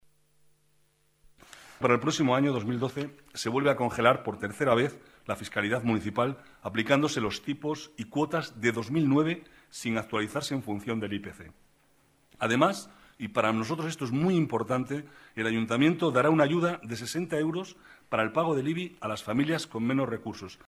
Nueva ventana:Declaraciones del vicealcalde, Manuel Cobo: Ayudas de 60 euros en el IBI para familias con pocos recursos